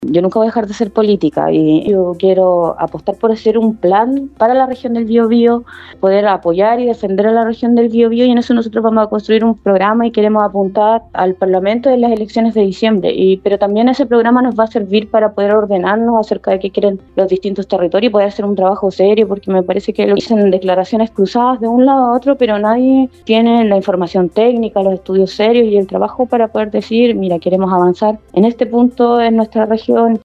En conversación con Radio Bío Bío, la exautoridad informó que está realizando una consulta ciudadana y el plan que elabora tiene como foco el trabajo preventivo de catástrofes como incendio, inundaciones o tsunamis.